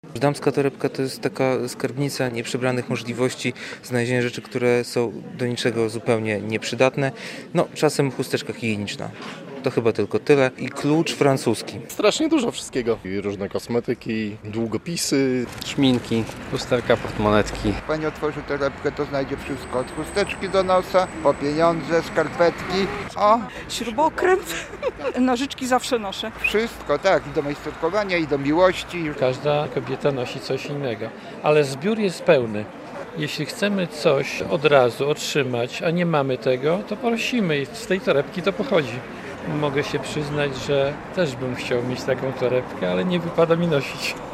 Na ulicach Białegostoku i Łomży można było spotkać naszych reporterów, którzy przygotowali wyjątkowy konkurs dla pań i nie tylko.